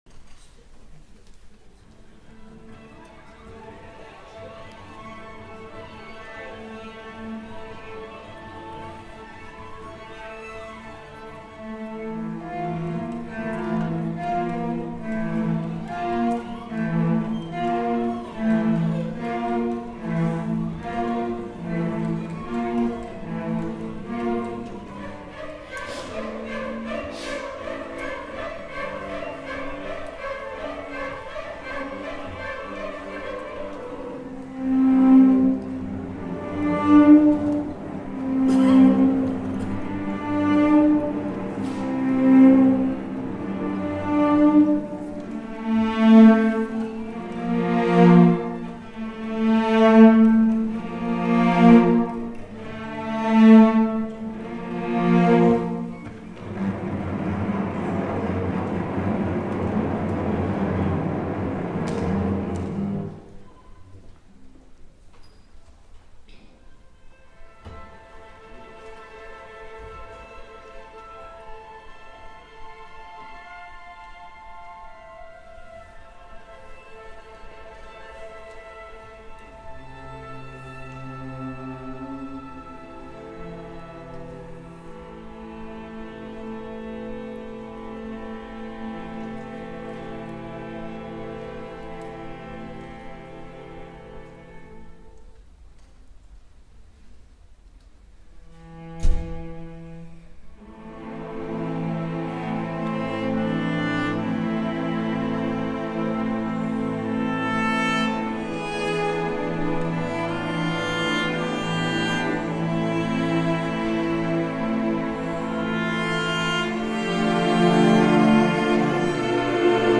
Le chant des oiseaux – Gautier Capuçon et 28 jeunes violoncellistes
casals-el-cant-dels-ocells-gautier-capuccca7on-et-28-enfants-violoncellistes..mp3